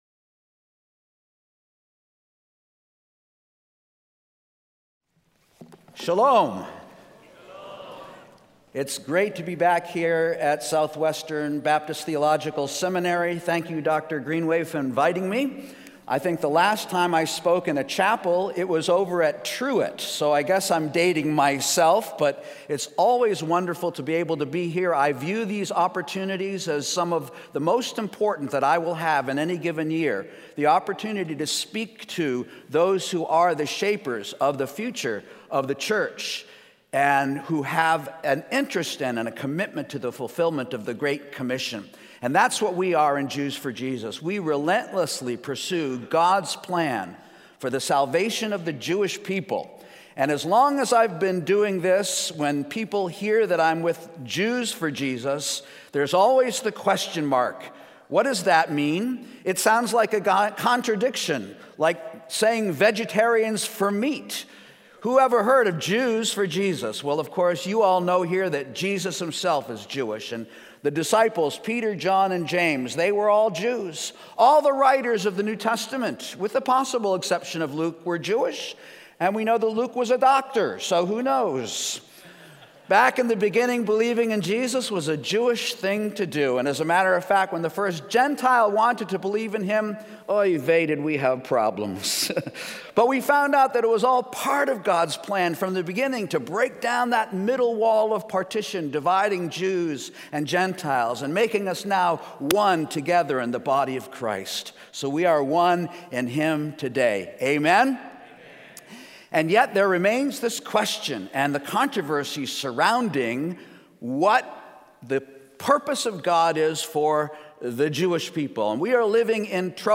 speaking on Isaiah 62 in SWBTS Chapel on Thursday November 14, 2019